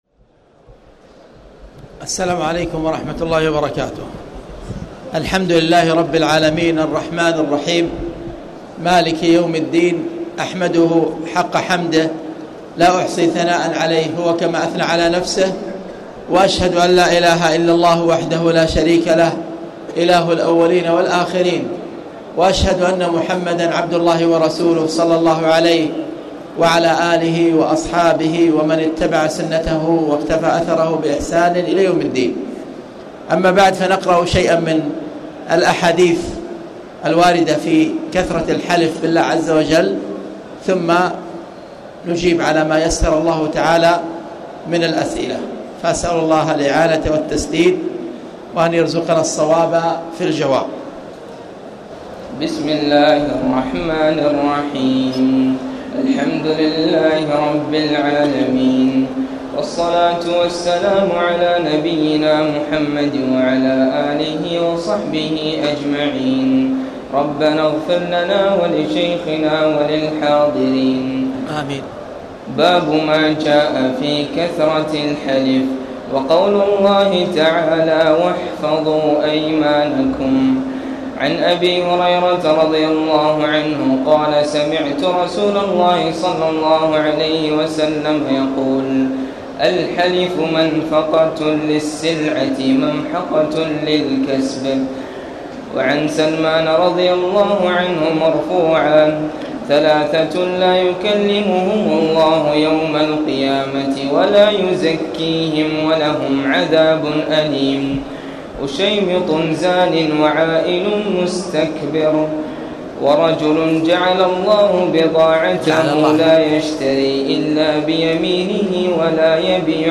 تاريخ النشر ٢٣ رمضان ١٤٣٨ هـ المكان: المسجد الحرام الشيخ: خالد بن عبدالله المصلح خالد بن عبدالله المصلح باب ما جاء في كثرة الحلف